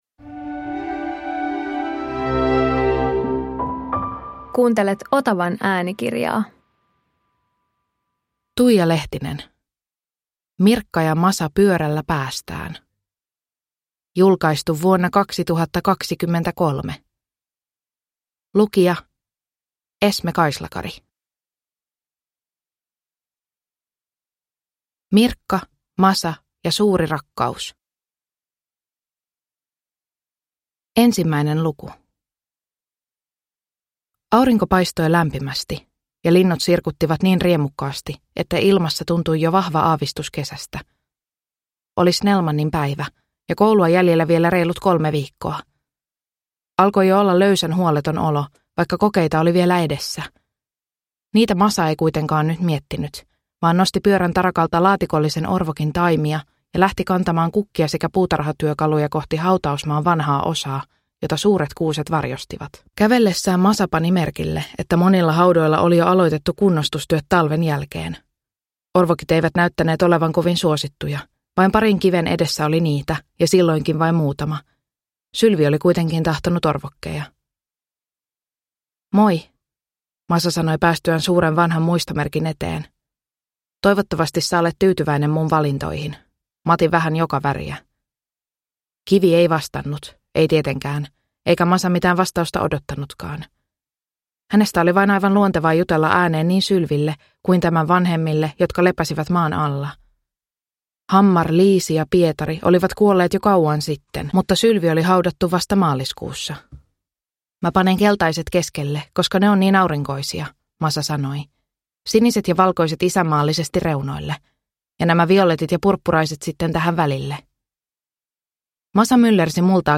Mirkka ja Masa pyörällä päästään – Ljudbok – Laddas ner
Äänikirja sisältää romaanit Mirkka, Masa ja suuri rakkaus (1995) sekä Mirkka, Masa ja uudet tuulet (1996).